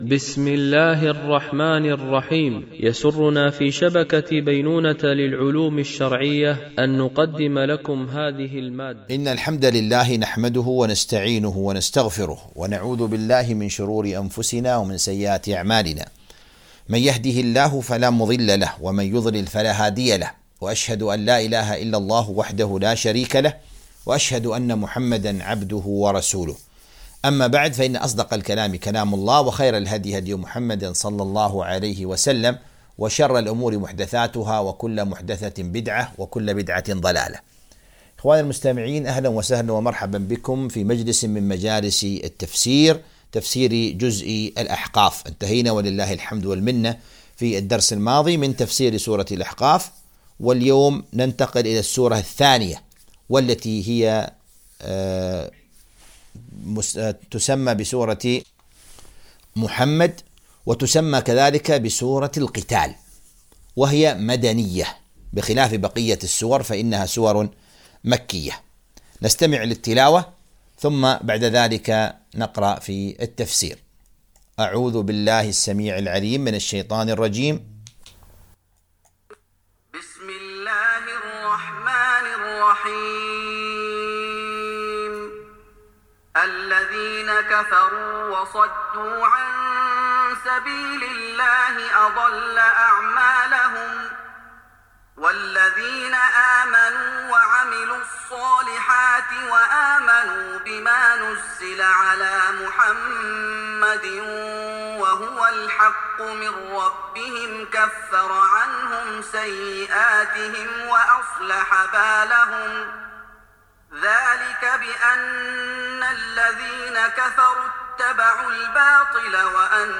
تفسير جزء الذاريات والأحقاف ـ الدرس 06 ( سورة محمد )